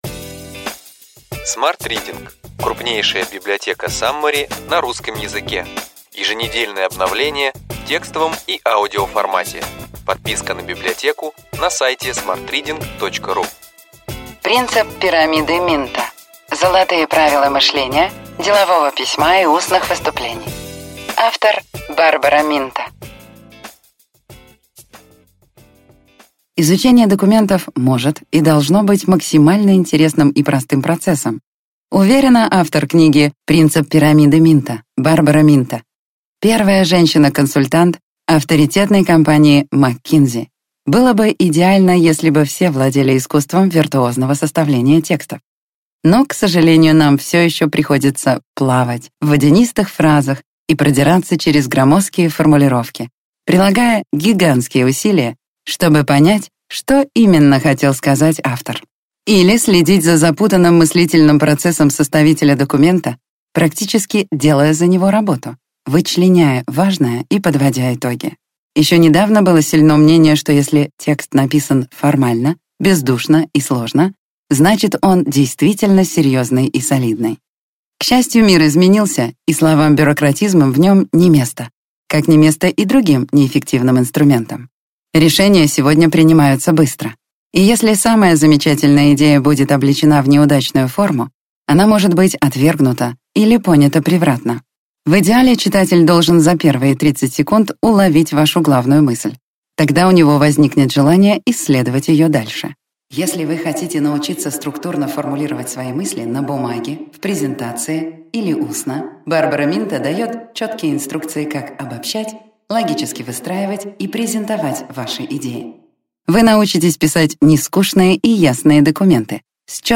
Аудиокнига Ключевые идеи книги: Принцип пирамиды Минто.